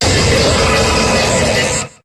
Cri de Regigigas dans Pokémon HOME.